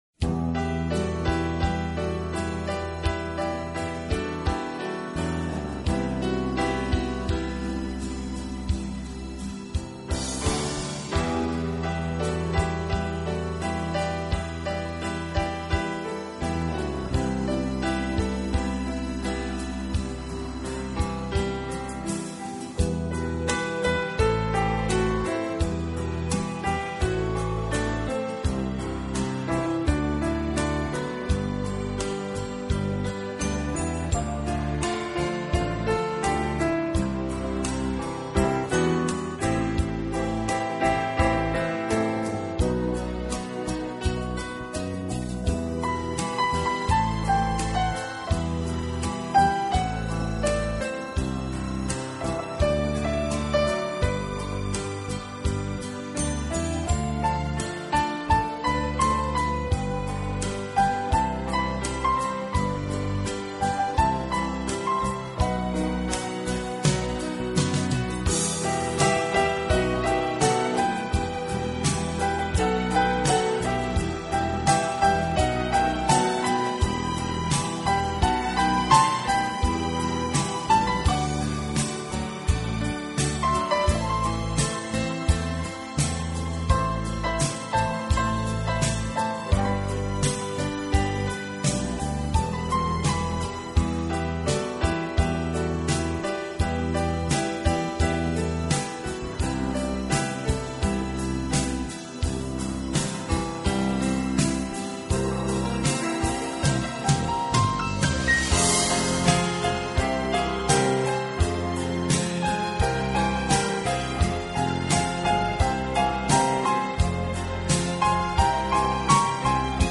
【名品钢琴】